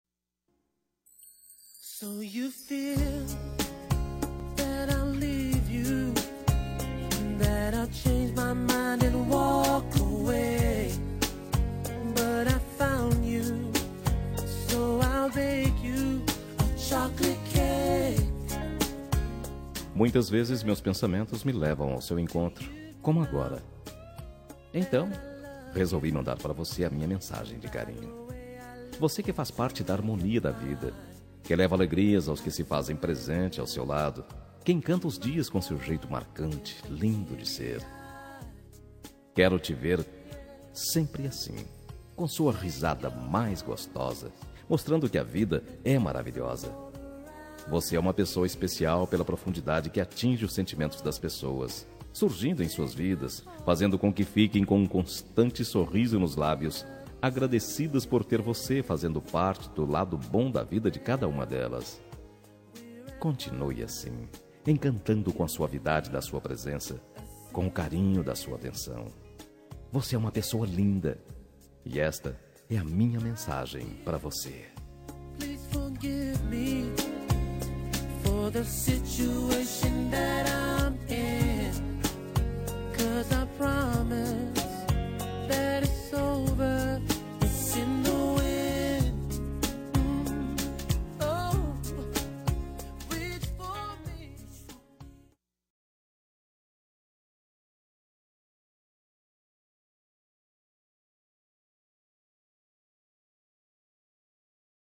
Telemensagem Você é Especial – Voz Masculina – Cód: 5423